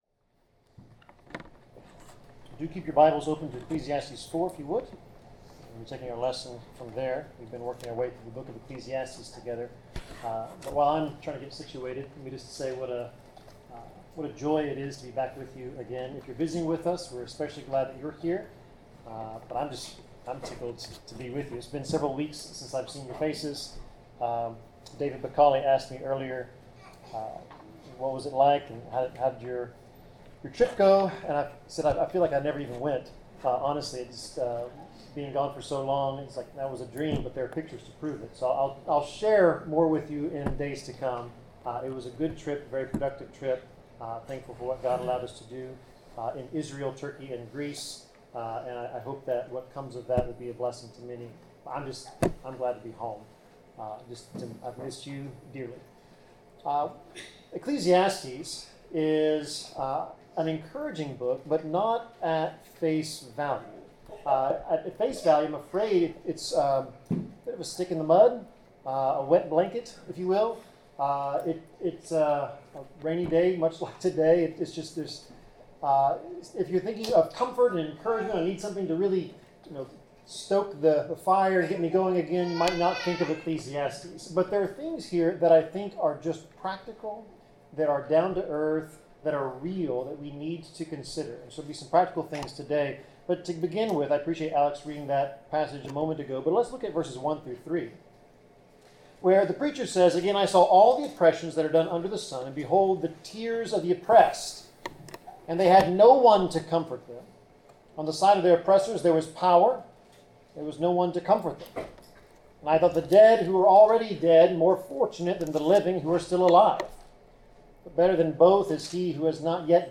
Passage: Ecclesiastes 4:4-12 Service Type: Sermon